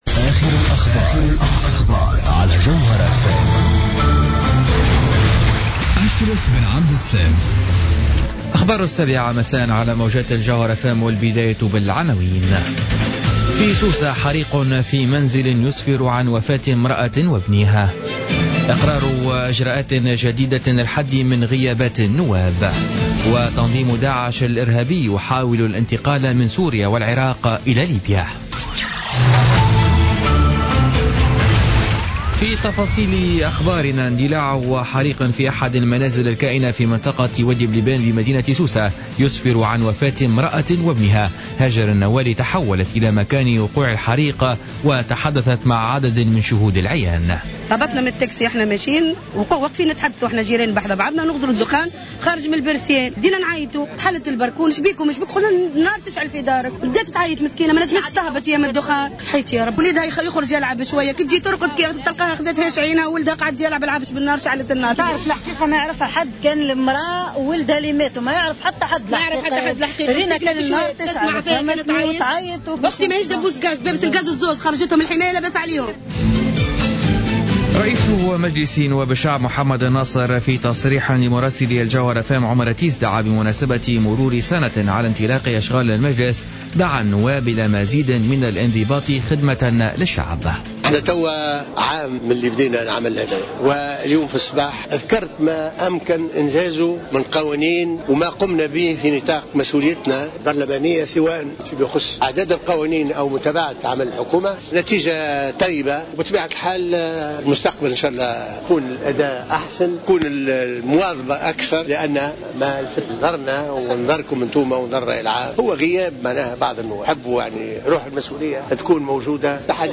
نشرة أخبار السابعة مساء ليوم الأربعاء 2 ديسمبر 2015